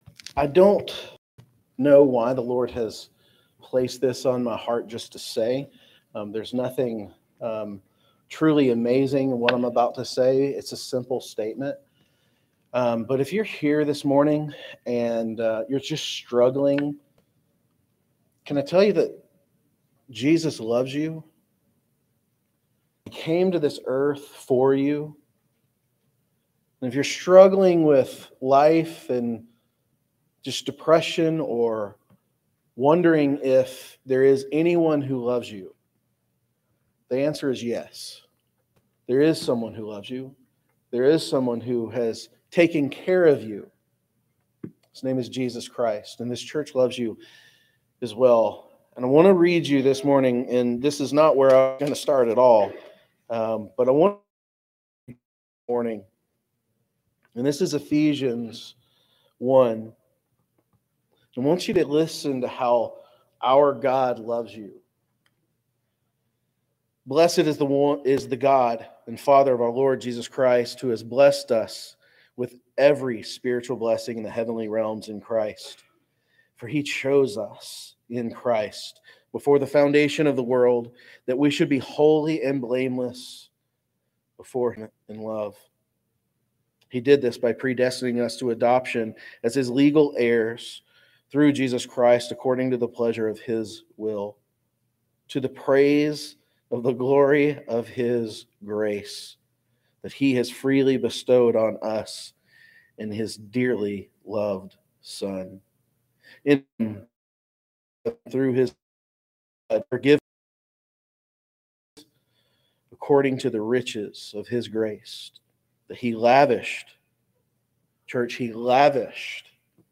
Sermons | Waleska First Baptist Church
Guest Speaker